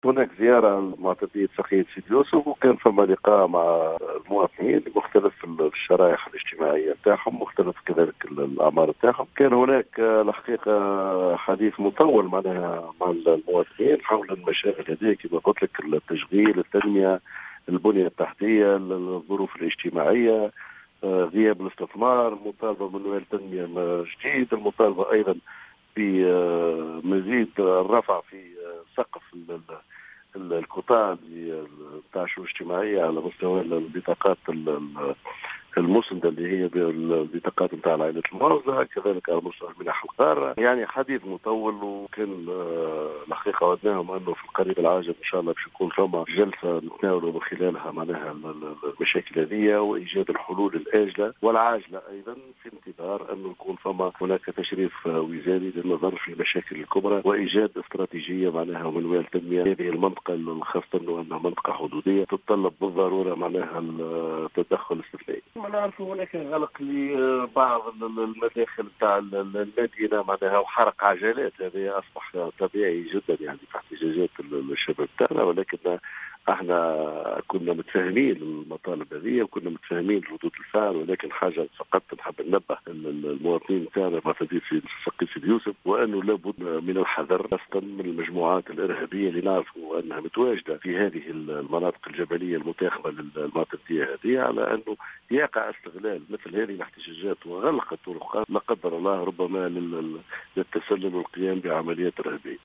وقال الوالي في تصريح لـ"الجوهرة أف أم" إن مطالب الاهالي مشروعة، لكنه دعا في المقابل إلى التعقّل والتهدئة و الاحتجاج السلمي، محذّرا من احتمال اندساس العناصر الإرهابية المتحصنة بالجبال المتاخمة للمنطقة واستغلالهم لهذه التحركات للتسلل والقيام بعمليات إرهابية، وفق تعبيره.